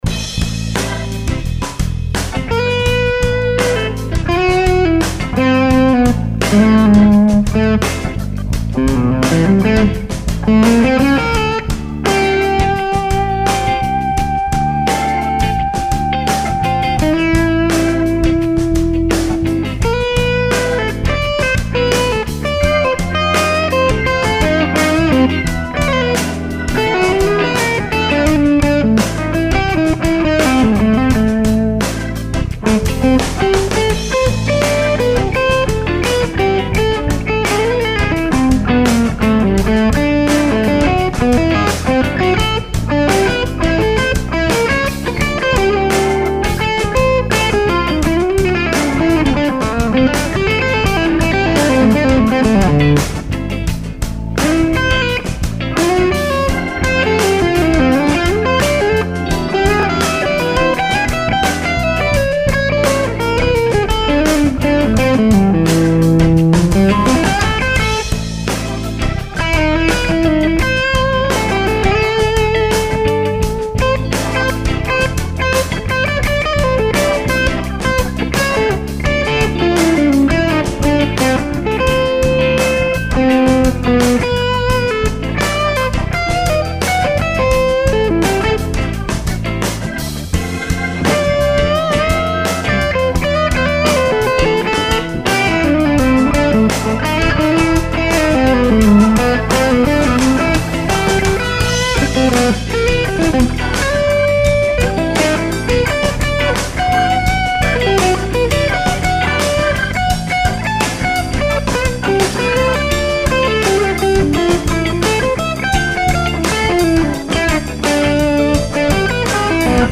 Here is my recent BM clip with just verb.